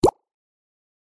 Звуки телефона Xiaomi
• Качество: высокое
Waterdrop preview